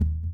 020-Kick_14.wav